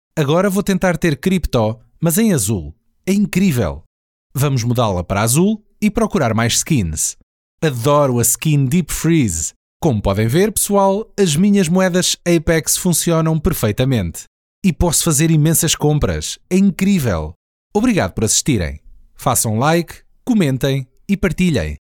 语言：葡萄牙语 （外籍葡萄牙语）
特点：大气浑厚 稳重磁性 激情力度 成熟厚重
风格:浑厚配音